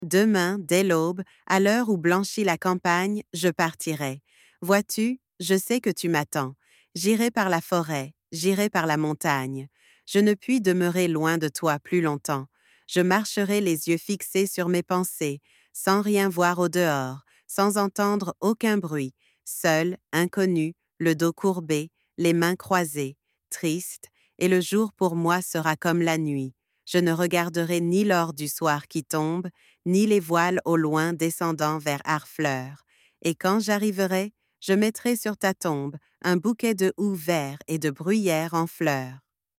multilingual text-to-speech
"voice": "Aria",
Generating speech with eleven_multilingual_v2 model...